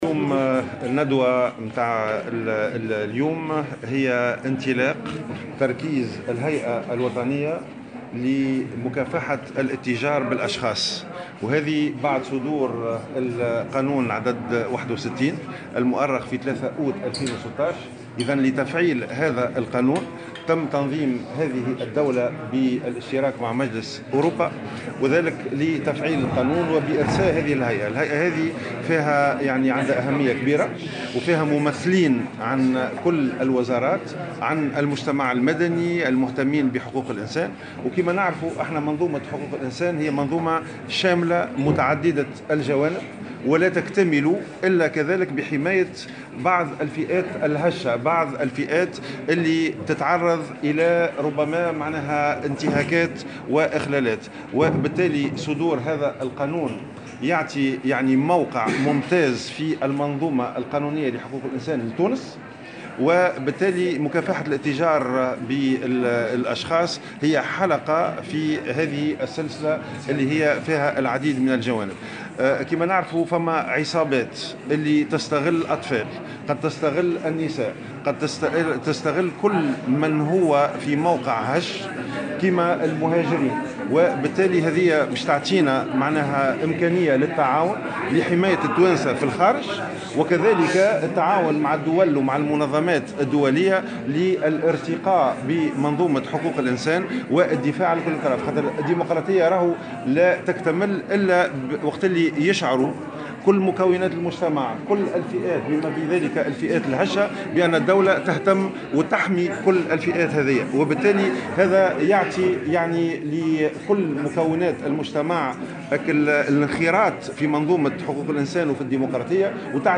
وقال في تصريحات صحفية لـ "الجوهرة أف أم" على هامش ندوة نظمت للغرض بالاشتراك مع مجلس أوروبا، إن هذه الهيئة تم تركيزها بعد صدور القانون 61 المؤرخ في 3 أوت 2016.